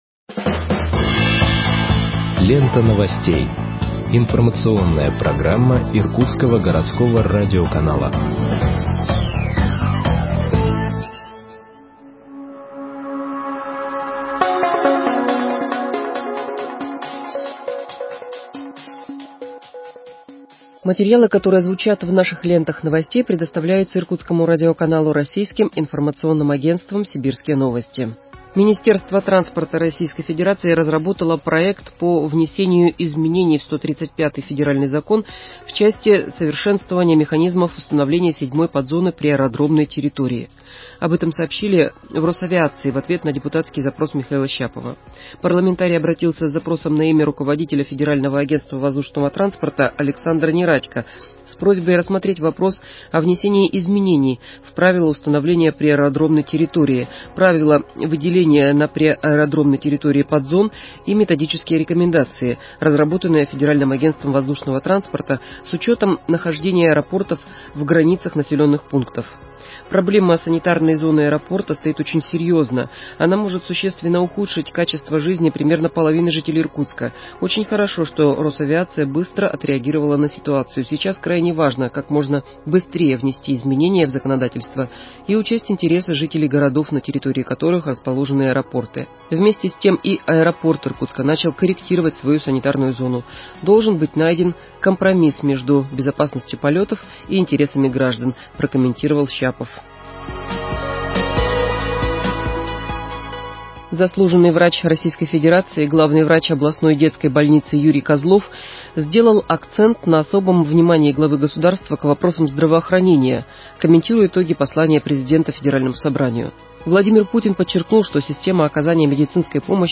Выпуск новостей в подкастах газеты Иркутск от 27.04.2021 № 1